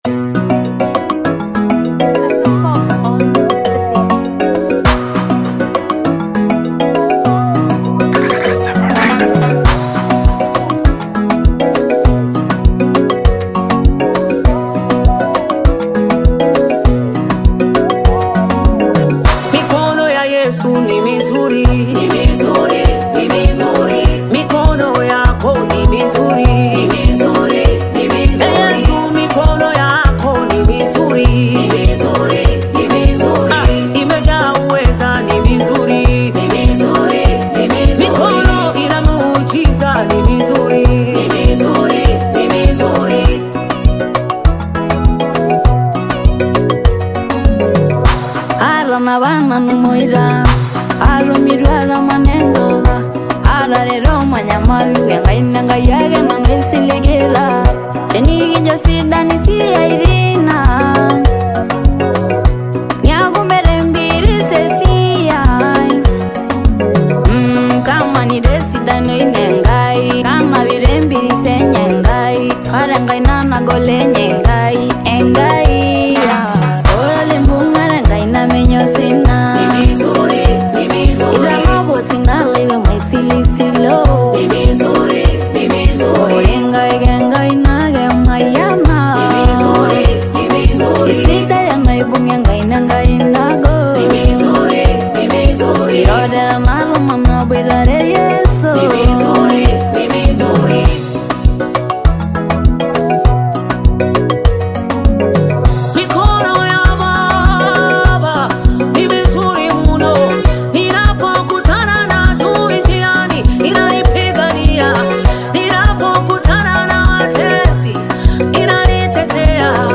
Tanzanian gospel singer and songwriter
gospel tradition song
African Music